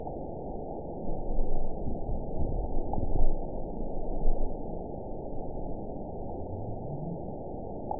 event 912240 date 03/21/22 time 23:43:47 GMT (3 years, 1 month ago) score 9.51 location TSS-AB03 detected by nrw target species NRW annotations +NRW Spectrogram: Frequency (kHz) vs. Time (s) audio not available .wav